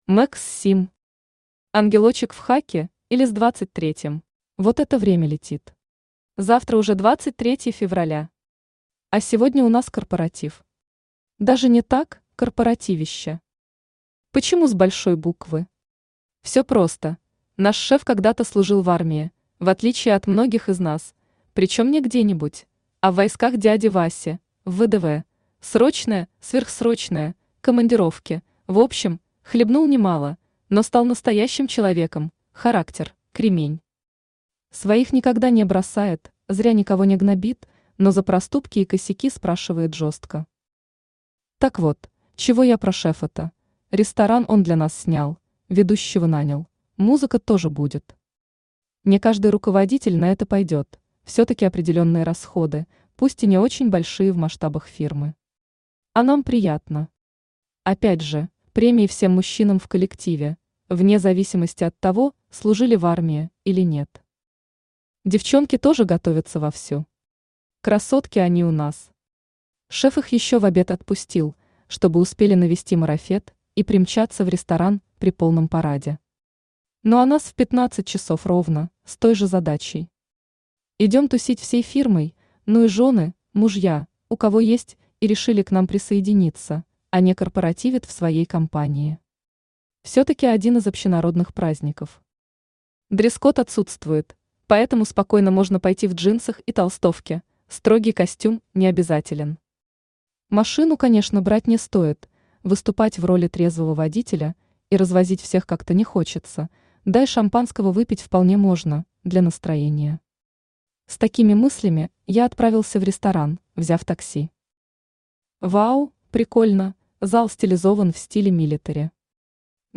Аудиокнига Ангелочек в хаки, или С 23-м | Библиотека аудиокниг
Aудиокнига Ангелочек в хаки, или С 23-м Автор Max Sim Читает аудиокнигу Авточтец ЛитРес.